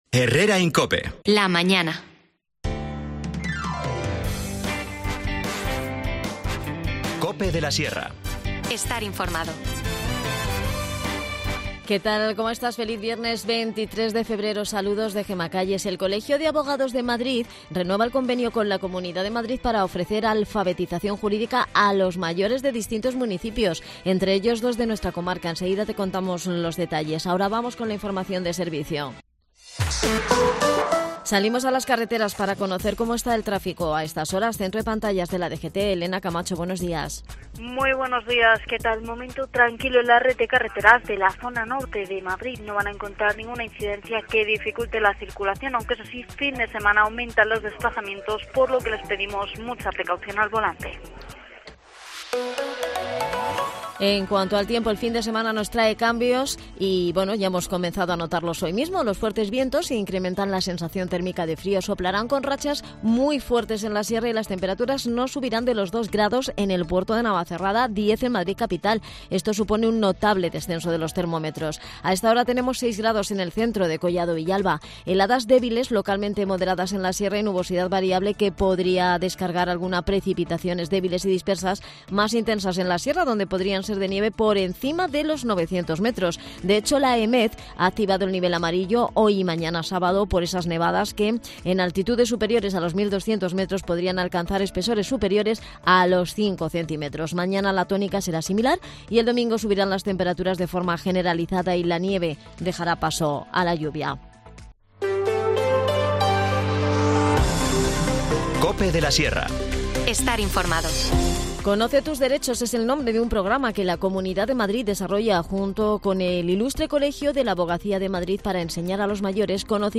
En nuestro tiempo de Deportes en Herrera en COPE hablamos con el concejal de Inclusión y Accesibilidad, José María Escudero.